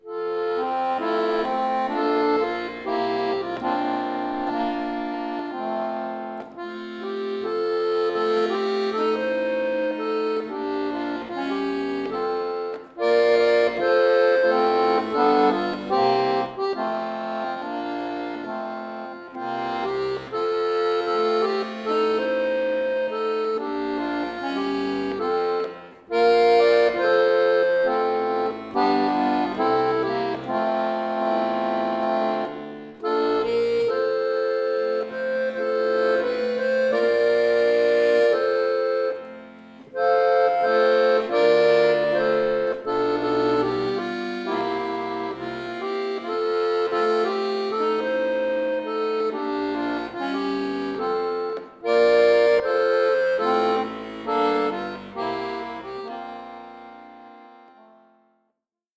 Traditioneller Folksong
neu arrangiert für Akkordeon solo
Folk